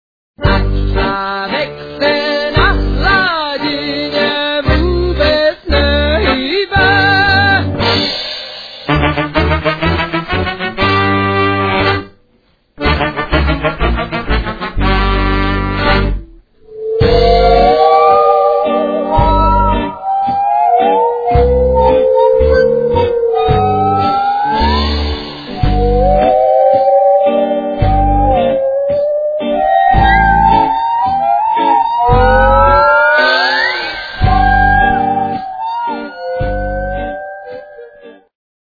saw